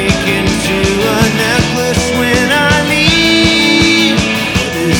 Example of Clipping